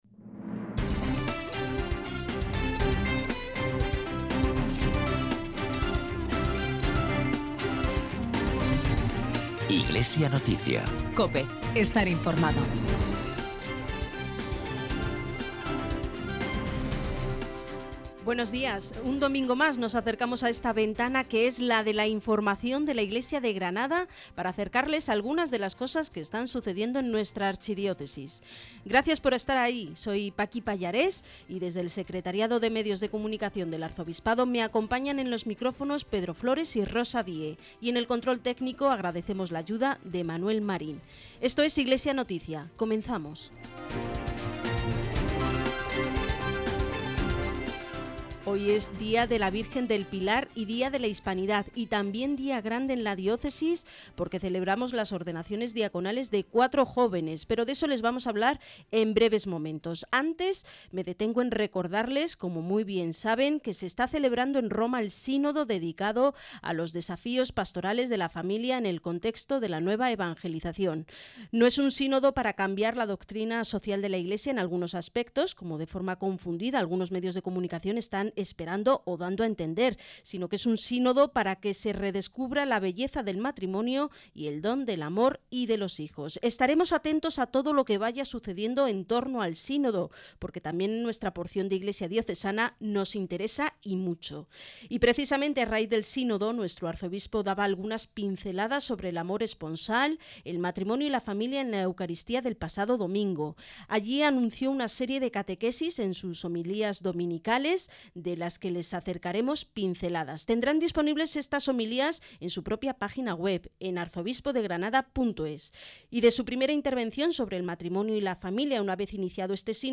En Iglesia Noticia, el informativo que realiza el Secretariado de Medios de Comunicación Social del Arzobispado de Granada en COPE Granada, emitido el domingo 12 de octubre de 2014.
Comenzamos el programa con la lectura del Santo Evangelio, correspondiente al XXVIII Domingo reproduciendo unas palabras de nuestro Arzobispo, Mons. Javier Martínez, en la homilía del domingo 5 de octubre, día en que comenzó el Sínodo de los obispos sobre la familia, por lo que Mons. Martínez habló del matrimonio y el amor esponsal y anunció una serie de catequesis sobre este tema para los próximos domingos, en la Misa dominical de la Santa Iglesia Catedral.
Asimismo, informamos de la ordenación de cuatro nuevos diáconos para la Iglesia, una muy buena noticia para la Diócesis y escuchamos algunas palabras de los candidatos al diaconado antes de la celebración.